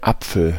Ääntäminen
Synonyymit cuit-pommes Ääntäminen France: IPA: /pɔ.mje/ Haettu sana löytyi näillä lähdekielillä: ranska Käännös Ääninäyte Substantiivit 1.